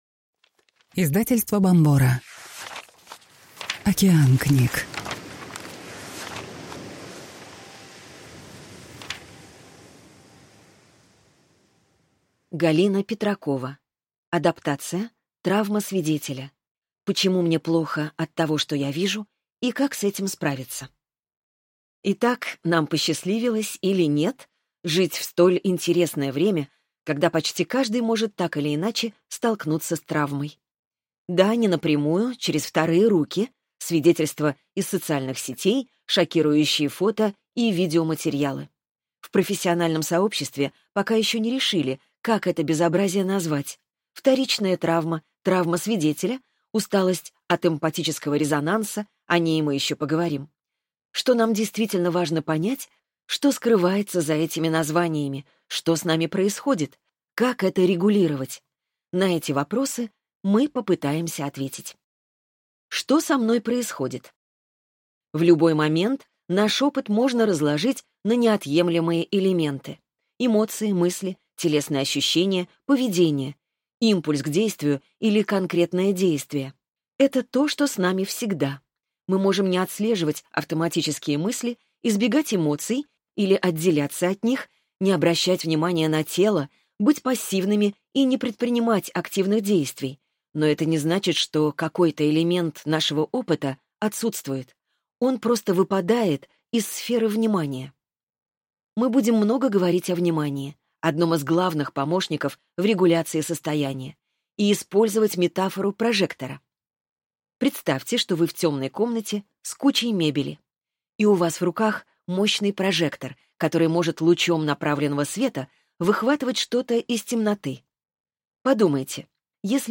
Аудиокнига Травма свидетеля. Почему мне плохо от того, что я вижу и как с этим справиться | Библиотека аудиокниг